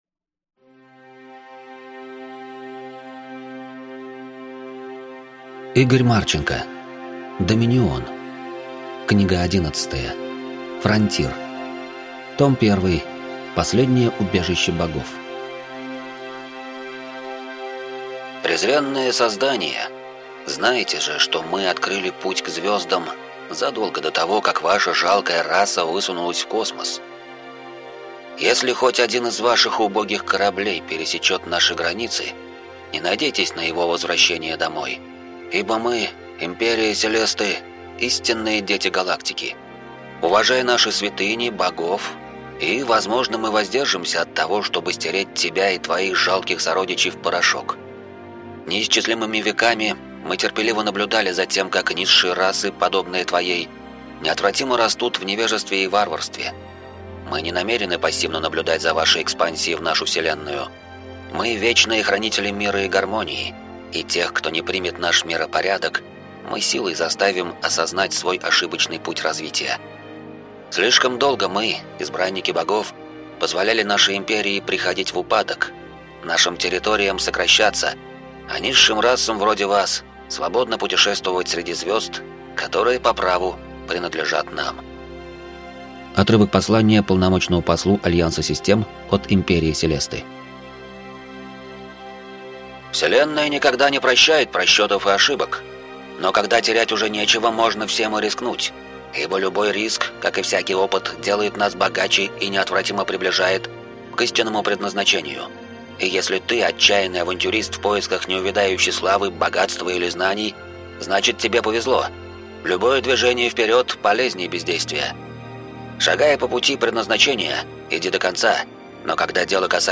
Аудиокнига Фронтир. Том 1. Последнее убежище богов | Библиотека аудиокниг